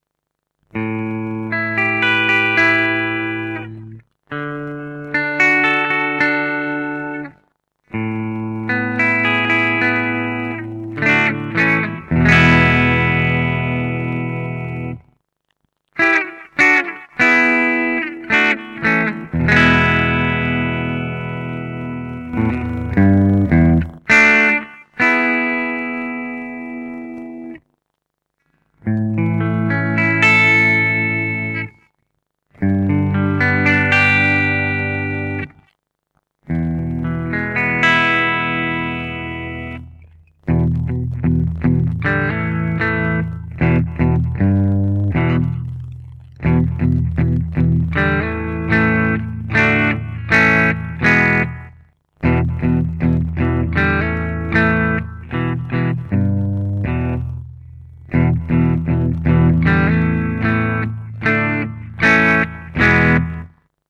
Delta Express vintage alnico 4 humbucker, vintage tone with extra bite
The treble and bass are is big and rounded, the mids are scooped with the emphasis on the lower registers.
The neck pickup is warm but clear while the bridge has hotter unbalanced coils to give you the extra drive needed and a touch more dirt.
Bridge Full      Bridge Tapped        Neck Full      Neck Tapped